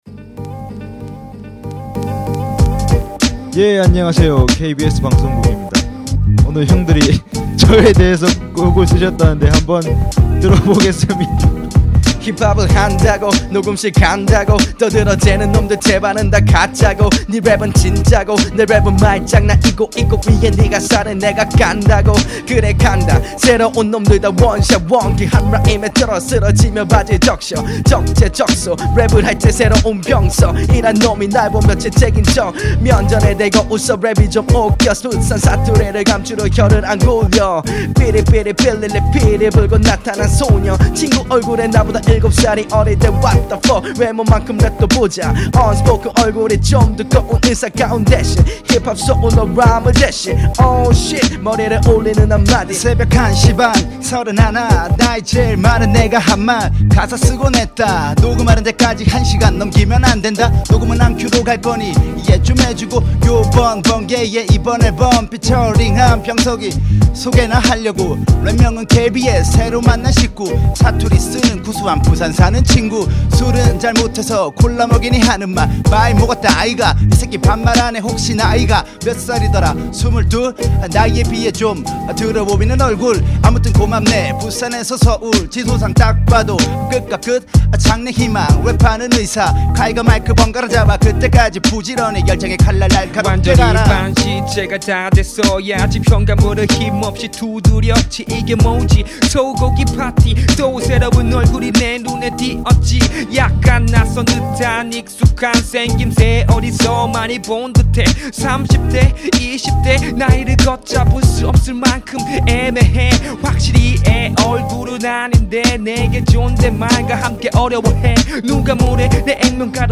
• [REMIX.]
ㅋㅋ 첫분 장난스럽게 뱉는듯한 플로우가 인상적이세요
분위기가 훈훈해서 좋네요 떱즈업